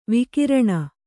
♪ vikiraṇa